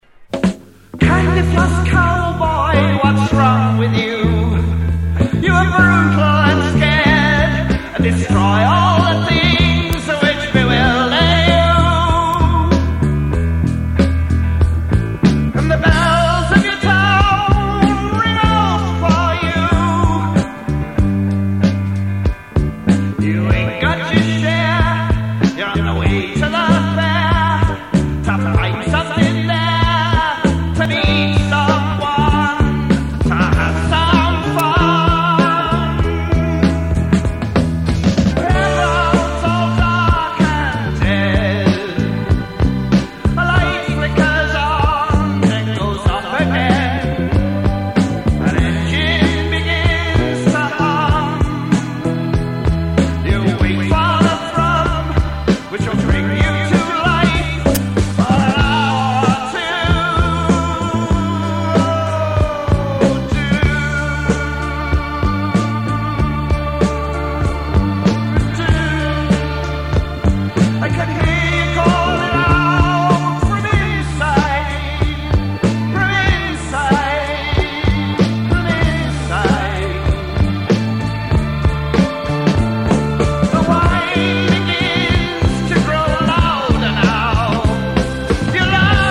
re-mastered from the original tapes